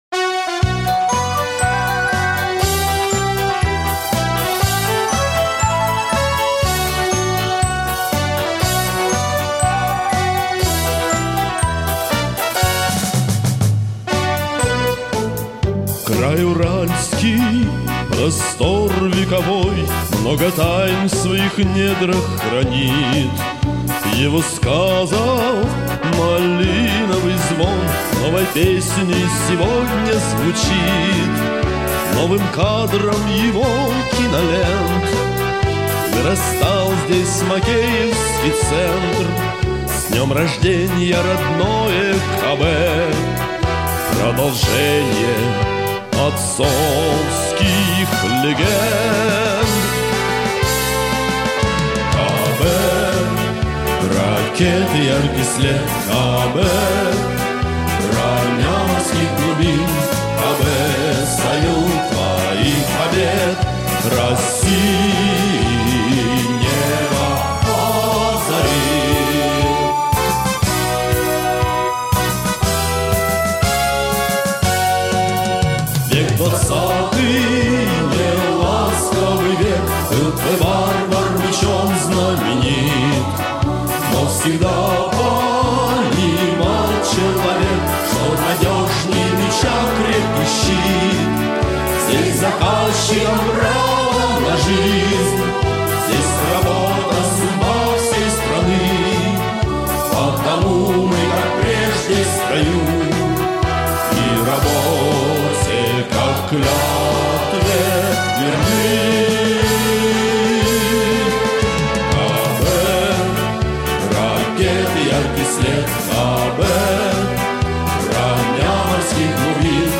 НК Академический хор ДКиТ «Прометей»